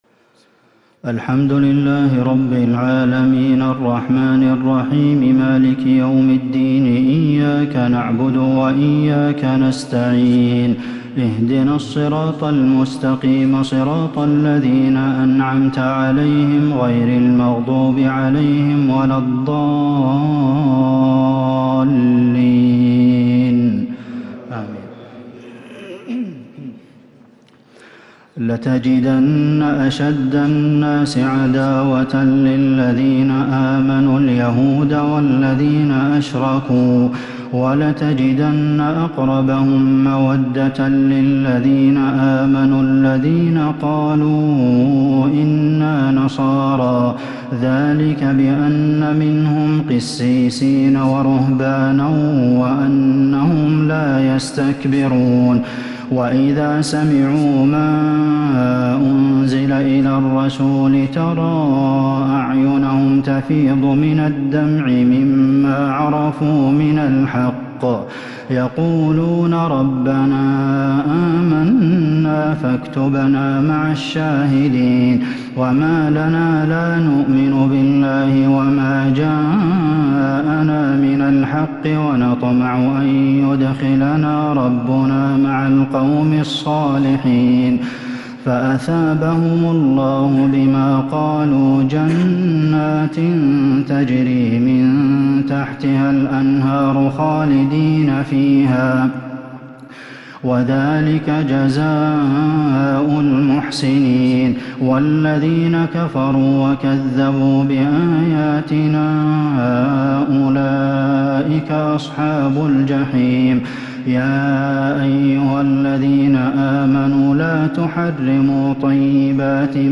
تراويح ليلة 9 رمضان 1442 خواتيم سورة المائدة وفواتح سورة الأنعام Taraweeh 9st night Ramadan 1442H > تراويح الحرم النبوي عام 1442 🕌 > التراويح - تلاوات الحرمين